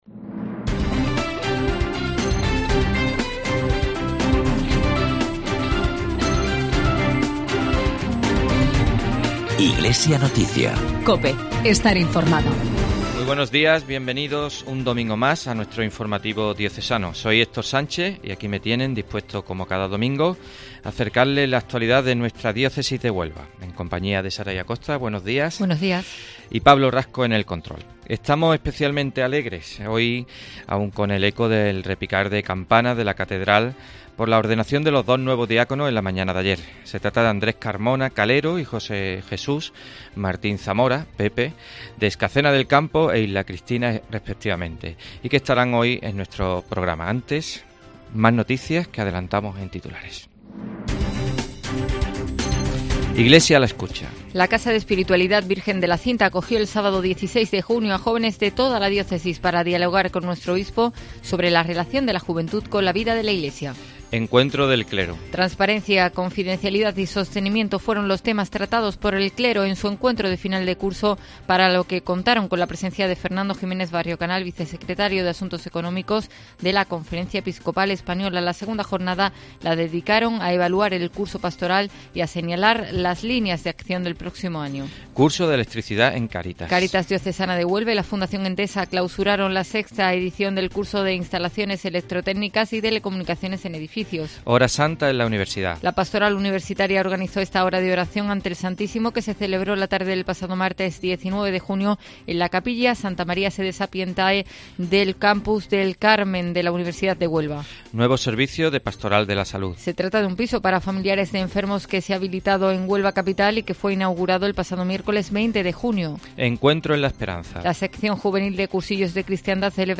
Redacción digital Madrid - Publicado el 26 jun 2018, 12:15 - Actualizado 15 mar 2023, 11:43 1 min lectura Descargar Facebook Twitter Whatsapp Telegram Enviar por email Copiar enlace La diócesis de Huelva cuenta desde el pasado sábado con dos nuevos diáconos. Hoy hablamos con ellos para conocer sus ilusiones e inquietudes. Además, informamos de todas las noticias de la Iglesia de Huelva.